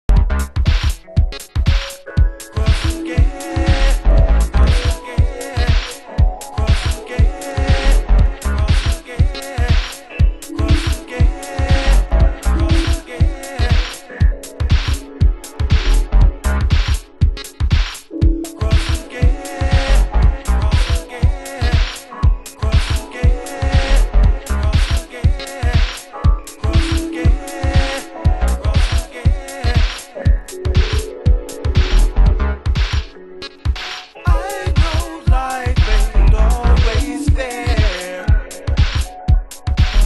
DEEP